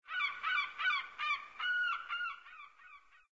SZ_DD_Seagull.ogg